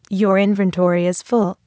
full_inventory.wav